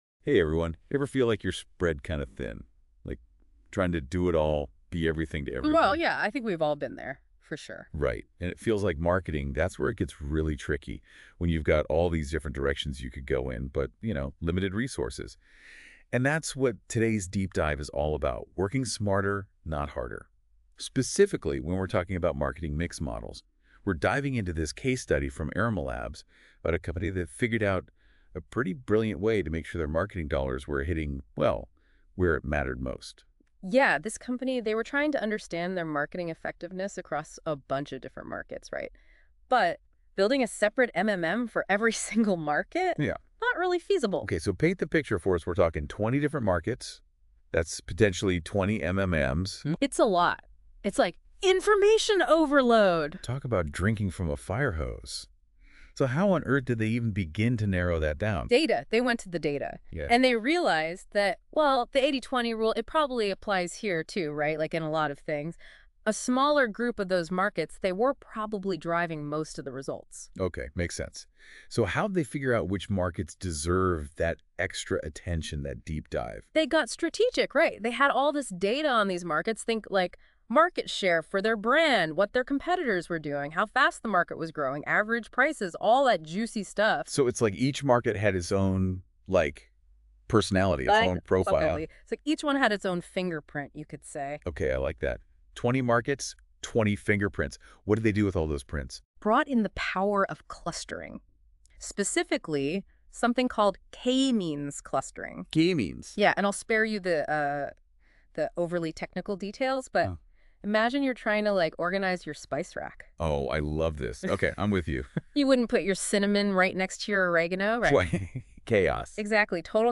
Note : This Podcast is generated through Notebook LM.